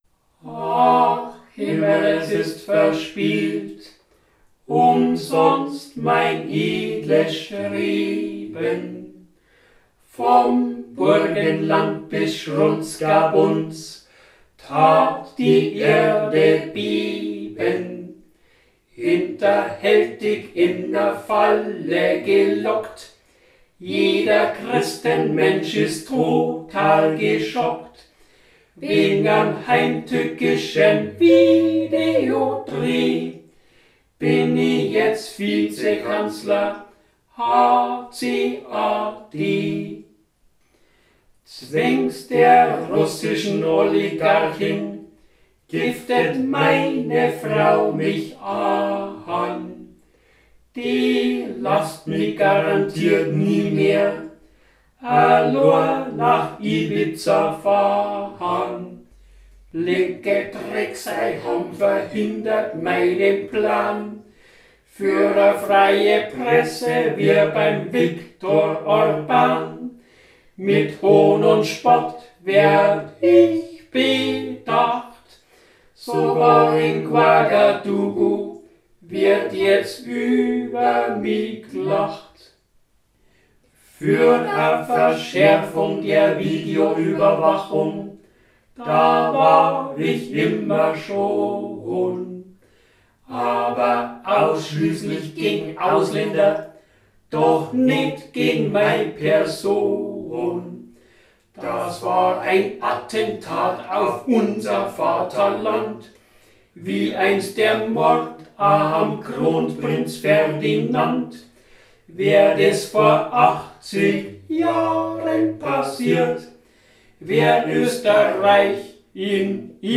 Lied zum Sonntag – Europawahl 2019